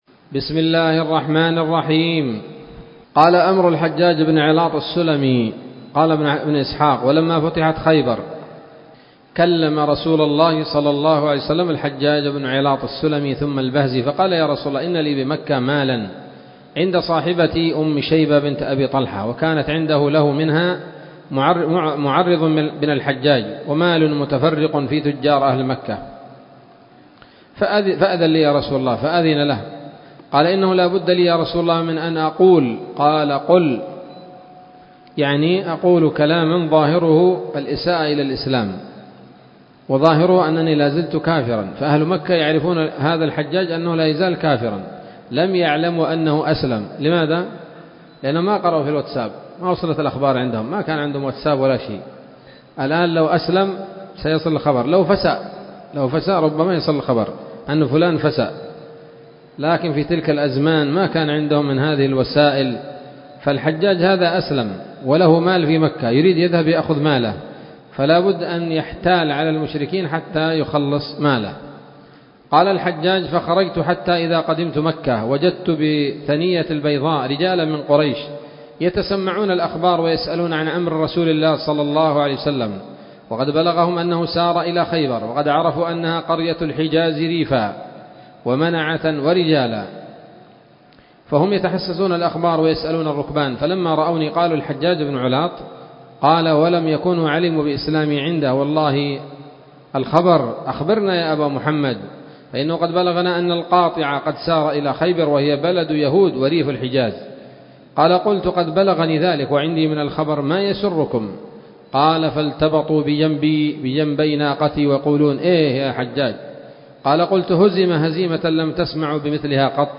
الدرس السادس والأربعون بعد المائتين من التعليق على كتاب السيرة النبوية لابن هشام